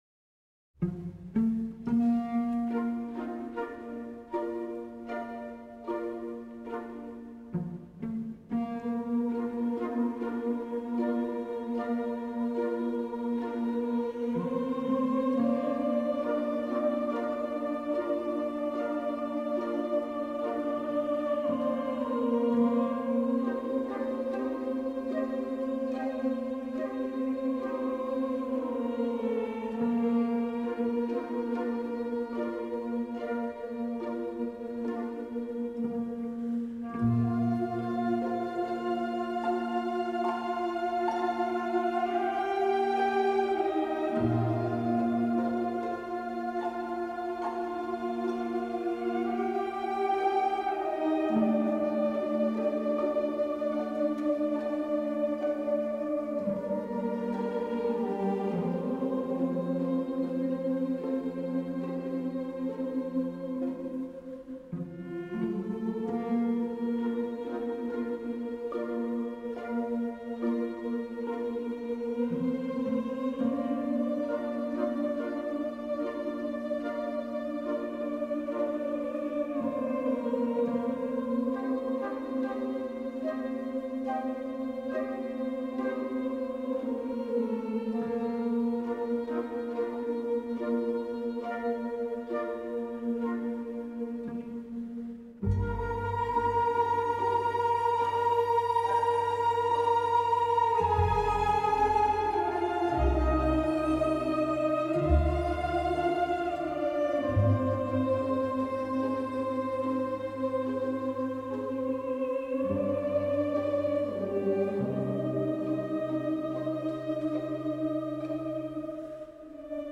Un extrait d’opéra par jour !
Cette fois-ci, ils y a de nombreux chanteurs ou choristes.
LUNDI 11 : “Madame Butterfly” de PUCCINI mais bouche fermée…